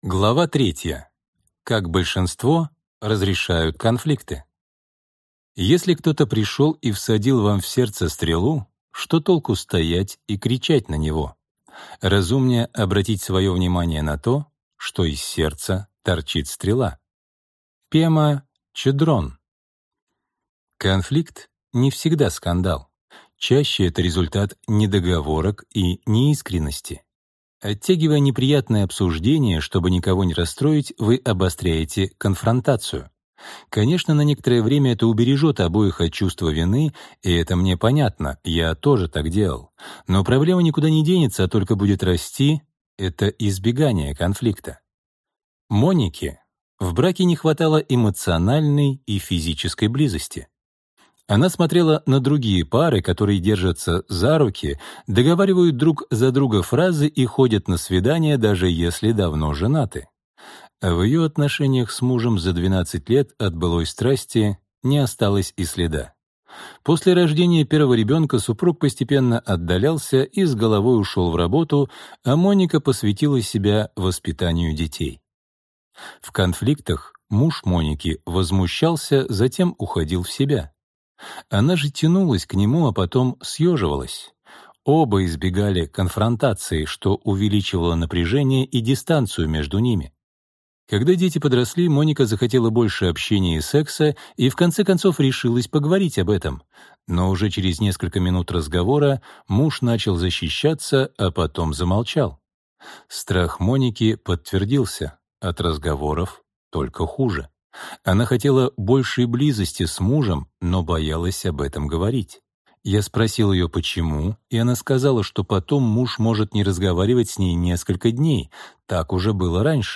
Аудиокнига Поговорим о нас | Библиотека аудиокниг